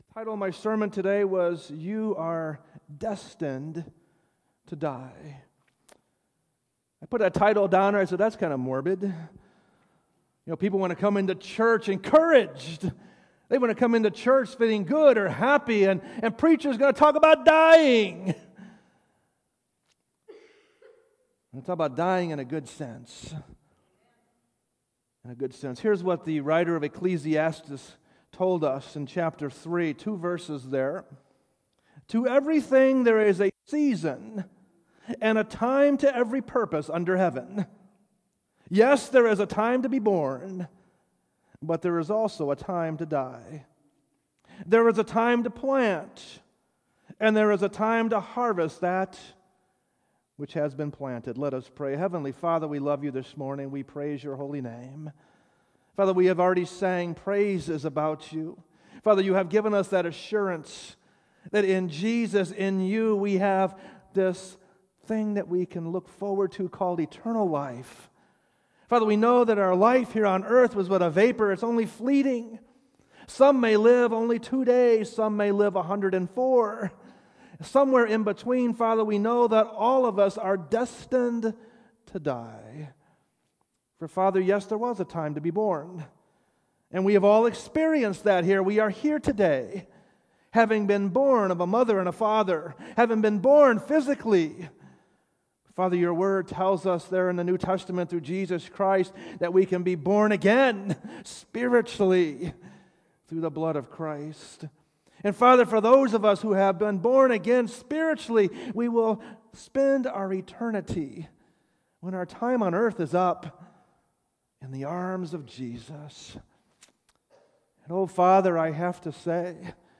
Sermons
sermons preached at Grace Baptist Church in Portage, IN